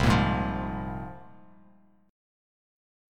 C7sus4#5 chord